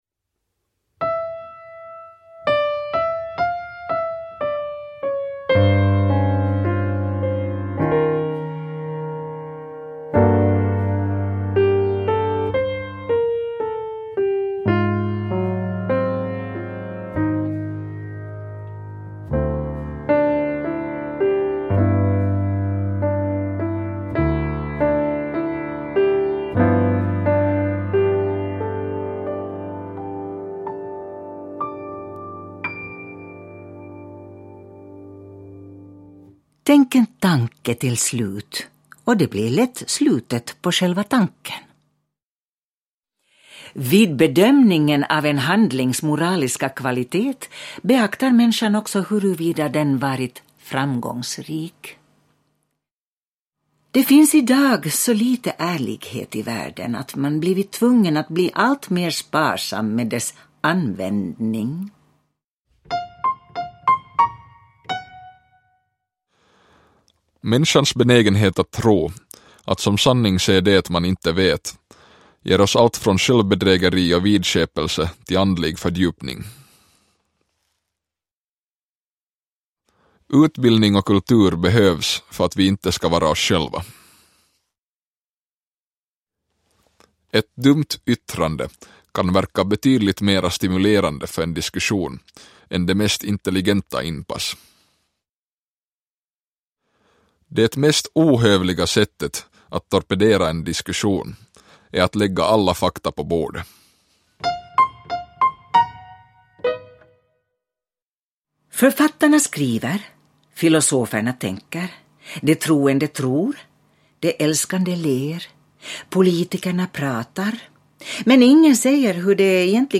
Musikvinjetter